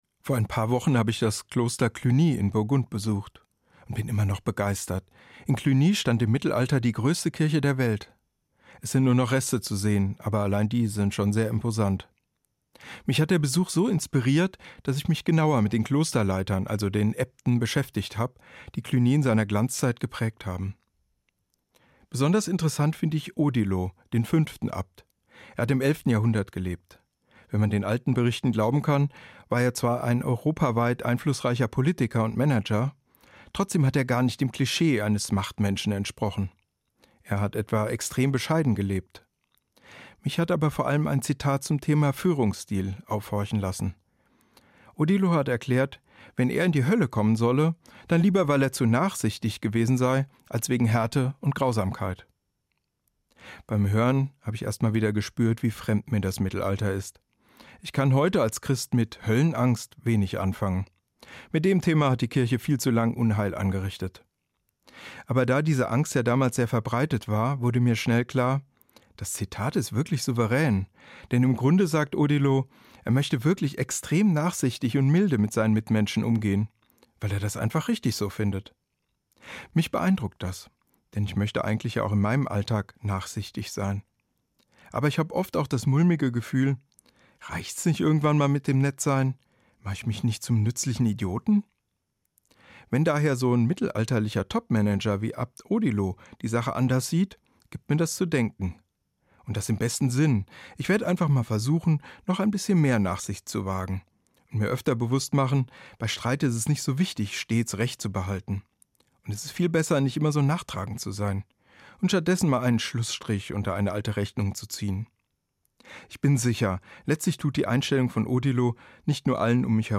Katholischer Religionslehrer, Rüsselsheim